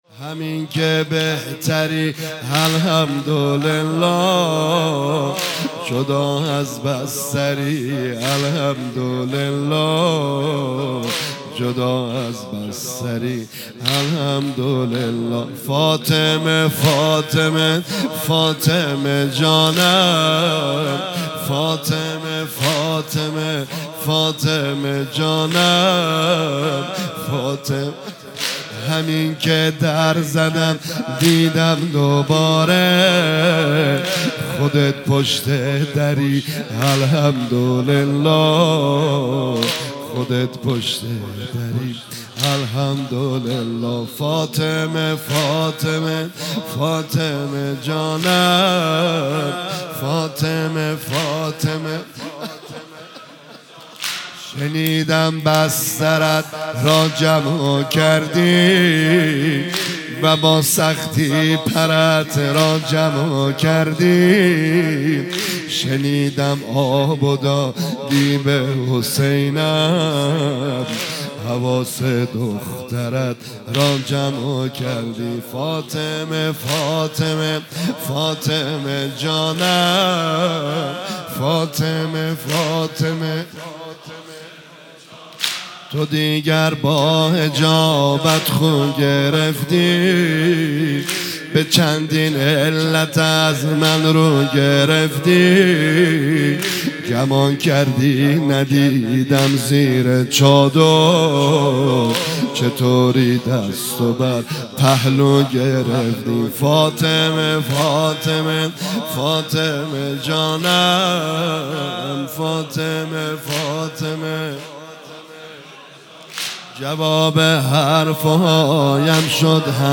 ایام فاطمیه 1399 | هیئت معظم کربلا کرمان